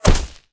bullet_impact_grass_02.wav